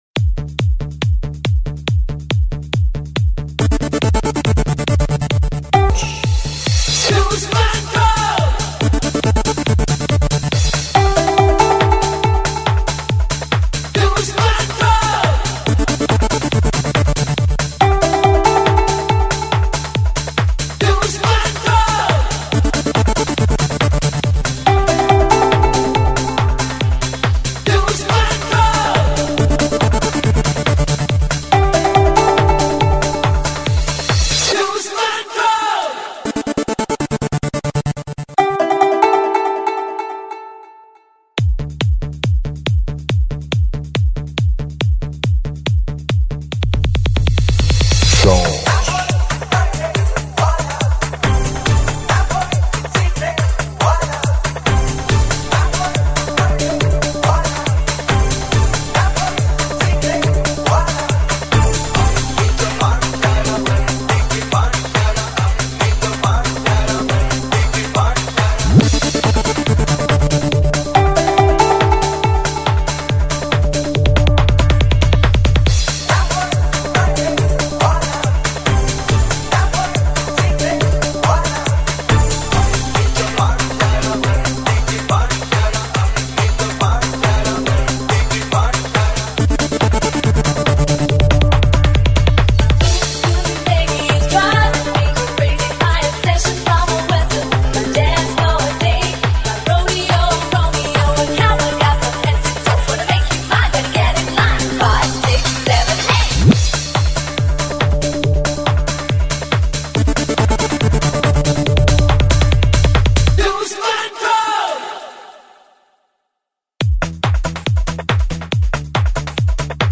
dance/electronic
Country-rock